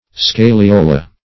scaliola - definition of scaliola - synonyms, pronunciation, spelling from Free Dictionary
Scaliola \Scal*io"la\, n.